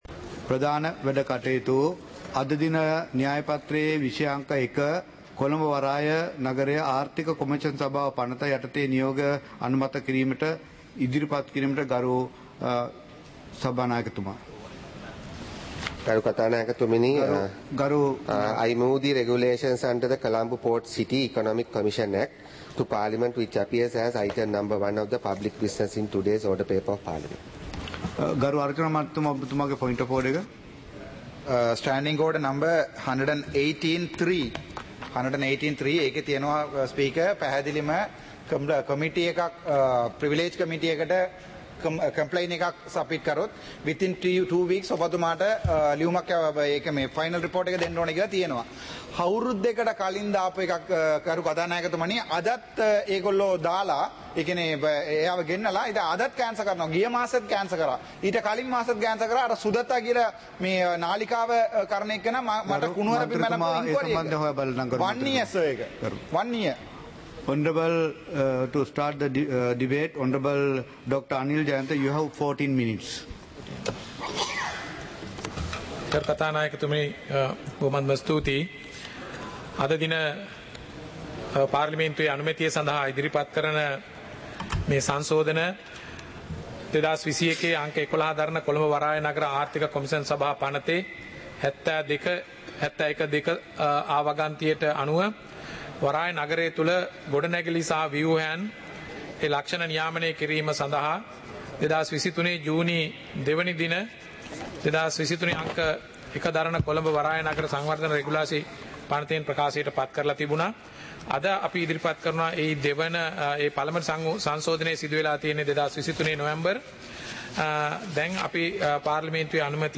சபை நடவடிக்கைமுறை (2026-03-19)